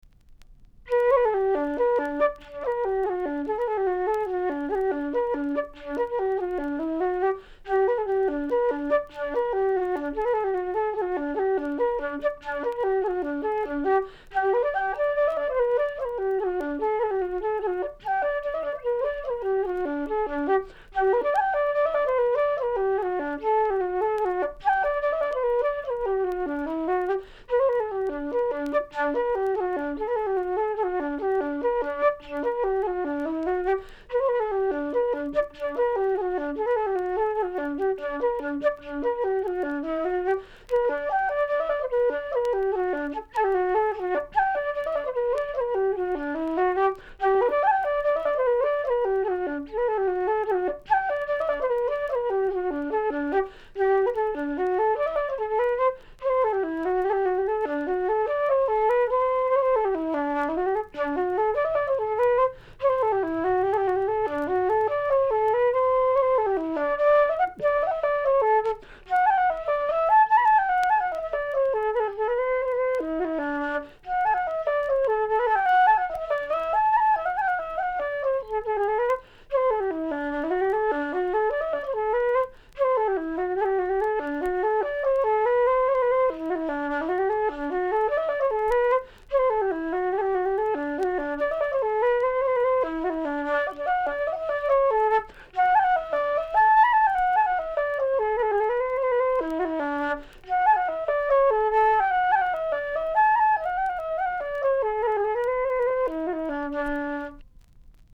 flute
in his lovely flowing manner